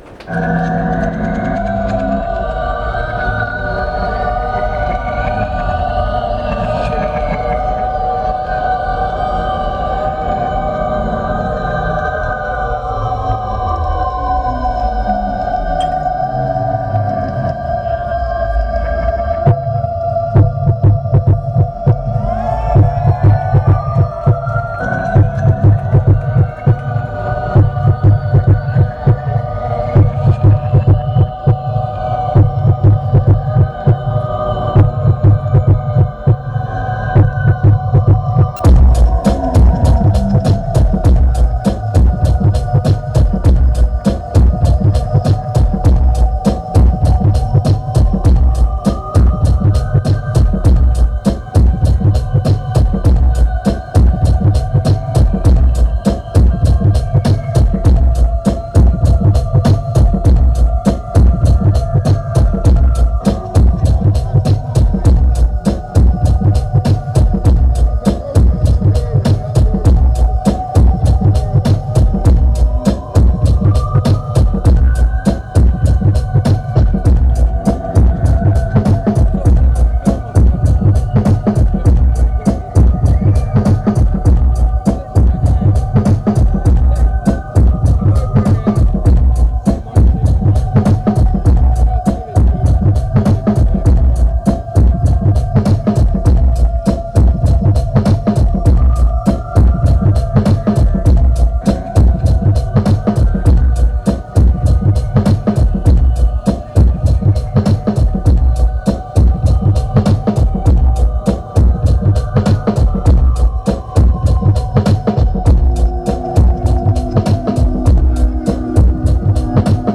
venue Camber Sands